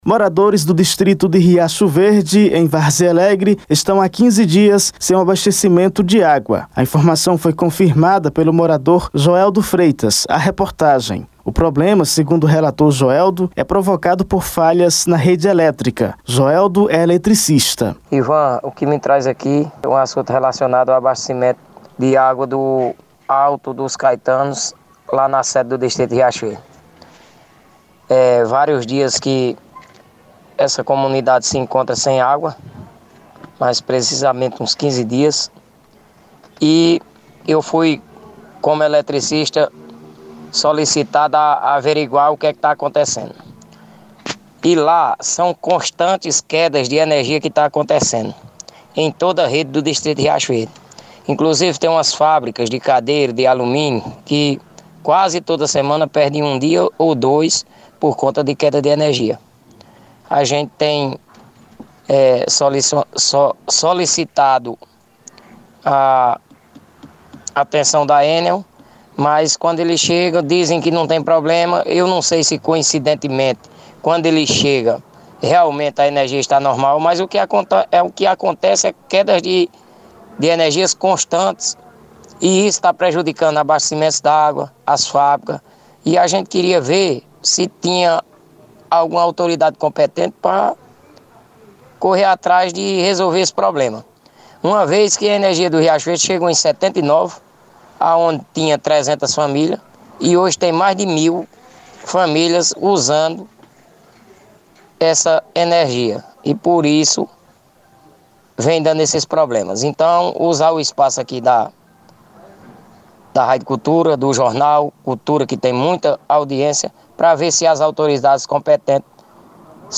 Confira o áudio da reportagem: Foto do Distrito | Redes sociais